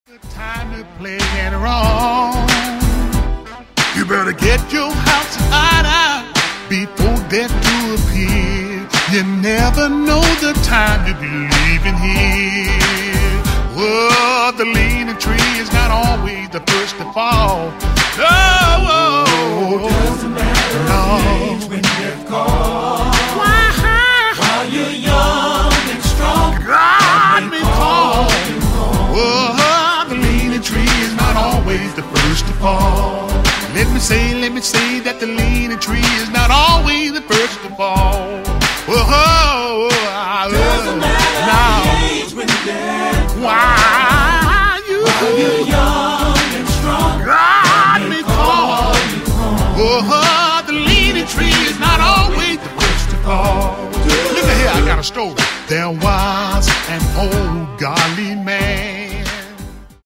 Taking Quartet Gospel To The Next Level!
There is music with a Southern Gospel style.